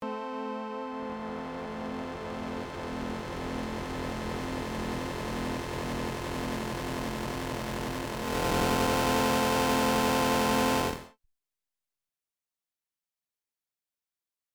Yesterday I finished the FM800 filter mod, and the sound has not quite what I expected to be, as there appears to be a lot of noise if I turn the knob open.
I attached an mp3 file with sound no#13 or #14 from the factory settings, while I turned the knob from close to open.